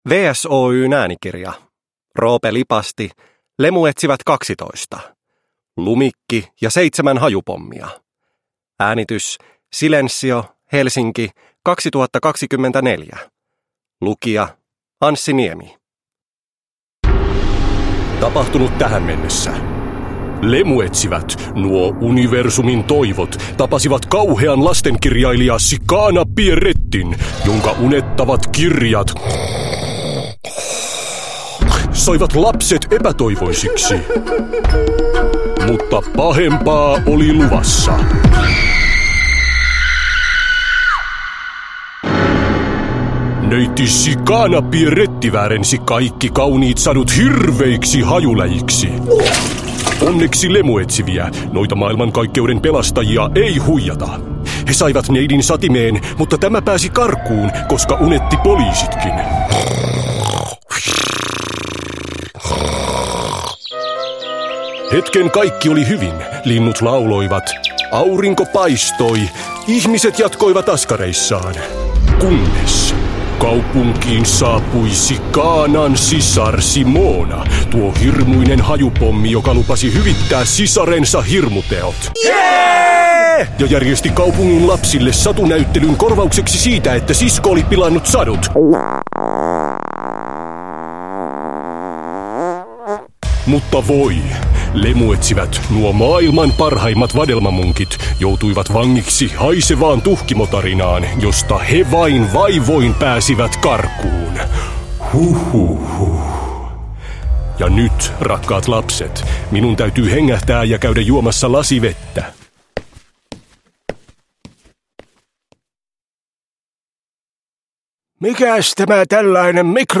Lemuetsivät 12: Lumikki ja seitsemän hajupommia – Ljudbok
Äänekästä ja ällöttävää audiosarjaa kuunneltu jo yli 50 000 kertaa!
Hulvaton äänikirjahitti Lemuetsivät jatkuu uusilla jaksoilla, joissa hassutellaan kaikille tuttujen satujen parissa ja kuullaan paljon ällöttäviä ääniefektejä.